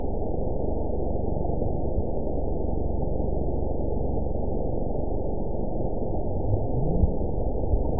event 917302 date 03/27/23 time 07:24:23 GMT (2 years, 1 month ago) score 9.18 location TSS-AB01 detected by nrw target species NRW annotations +NRW Spectrogram: Frequency (kHz) vs. Time (s) audio not available .wav